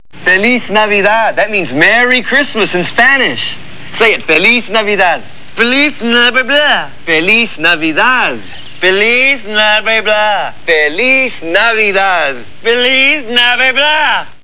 Pee Wee Herman trying to say "Feliz Navidad" in Spanish